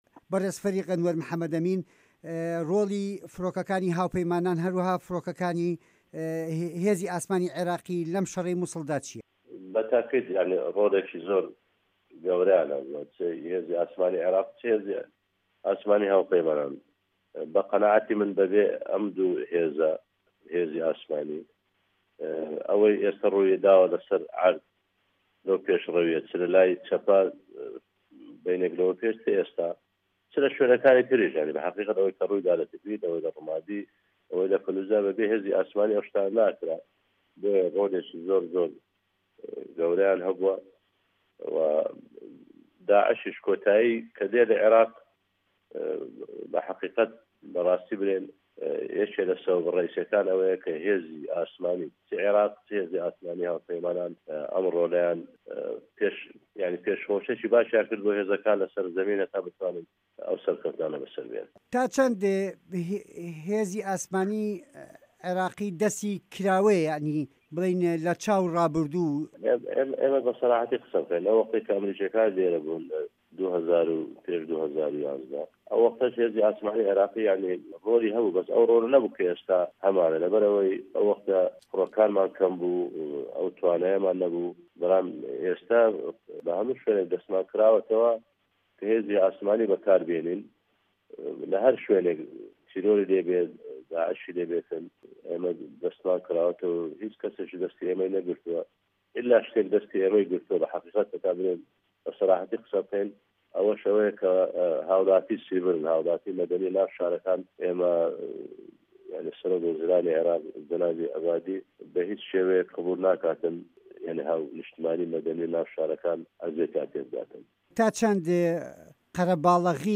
وتووێژ لەگەڵ فه‌ریق ئه‌نوه‌ر حه‌مه‌ ئه‌مین